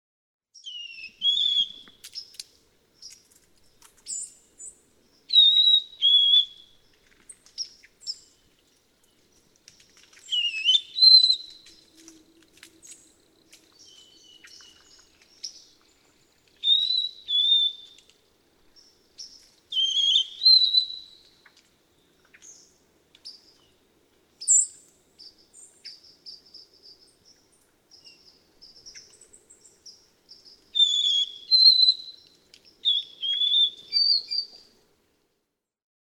White-throated sparrow
♫172. Plastic song by four-month-old male.
Hawley Bog, Hawley, Massachusetts.
172_White-throated_Sparrow.mp3